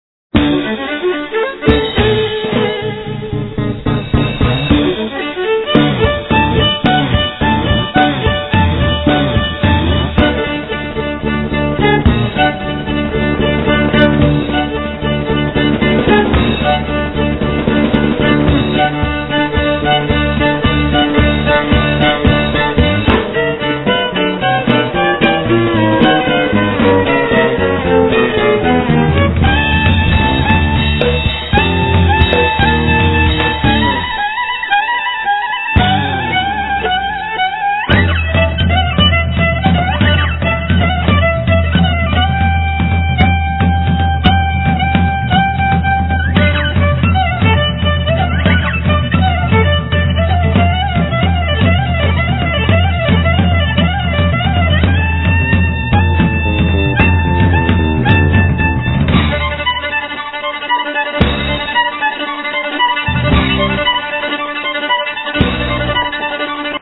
Viola, Vocals
Guitar-bass
Drums